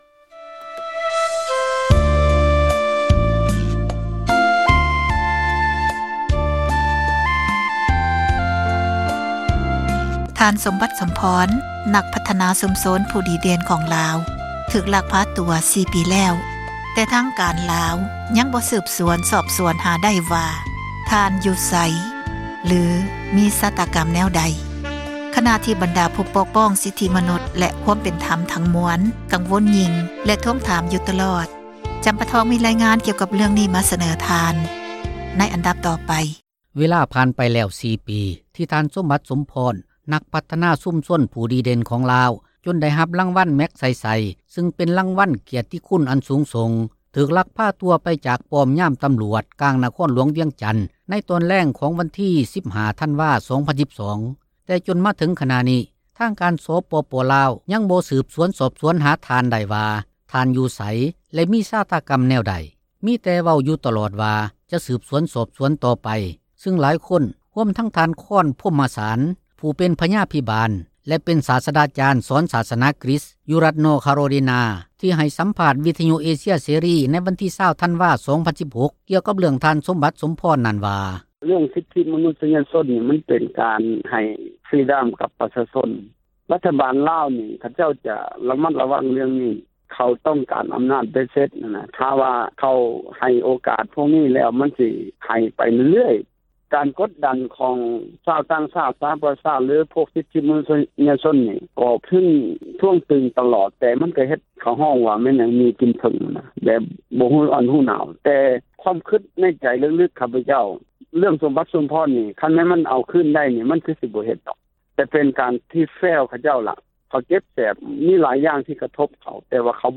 ມີຣາຍງານ ມາສເນີທ່ານ ເຊີນຮັບ ຟັງໄດ້.